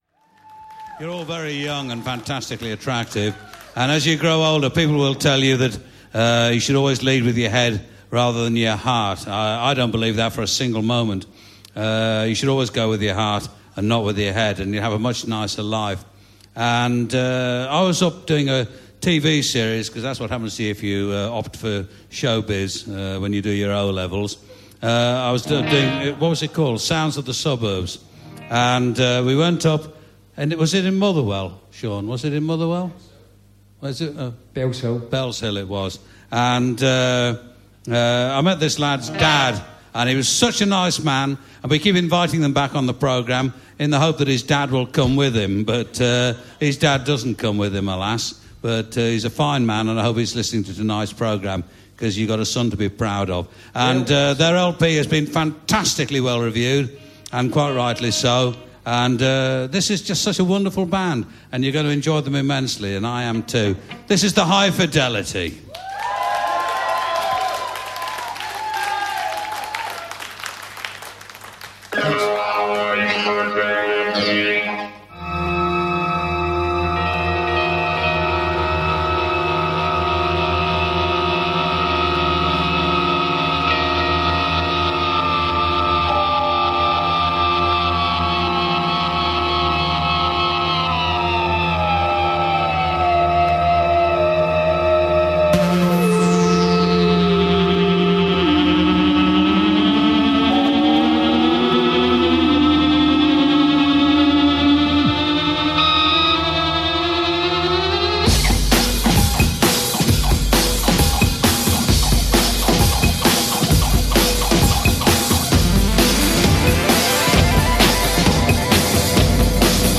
British rock band